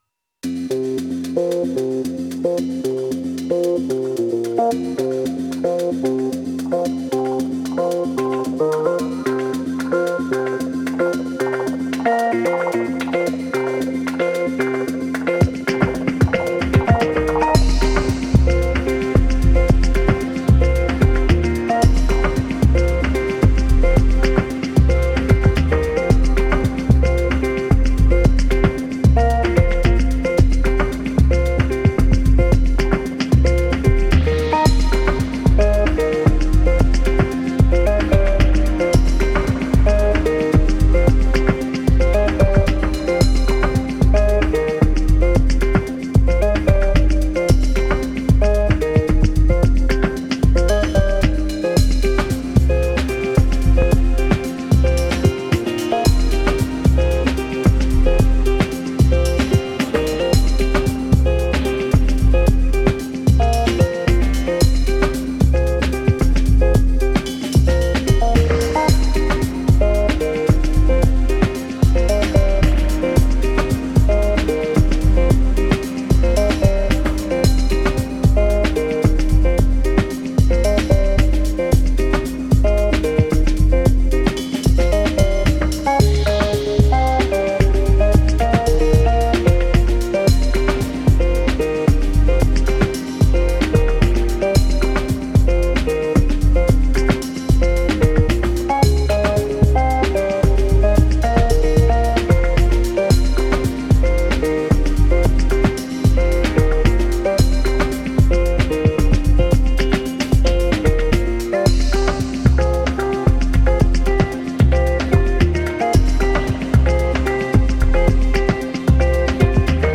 Genre: Downtempo, Ambient.